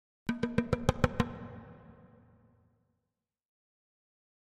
Drums Short Drumming 4 - Slow Hits - Medium Drum